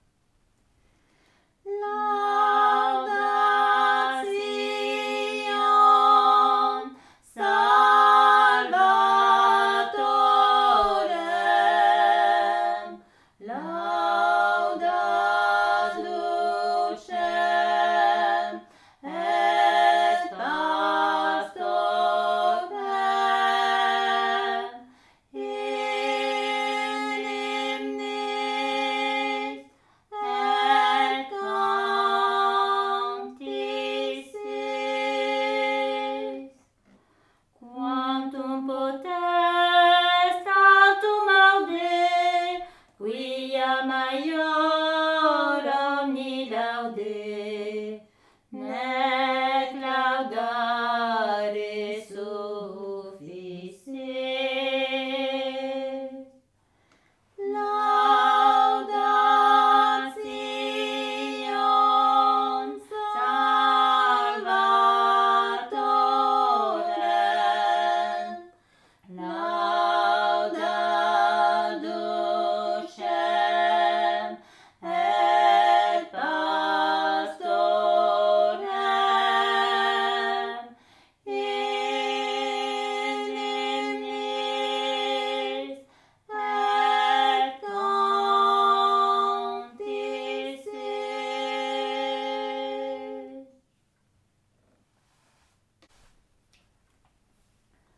Aire culturelle : Bigorre
Lieu : Ayros-Arbouix
Genre : chant
Effectif : 2
Type de voix : voix de femme
Production du son : chanté
Classification : cantique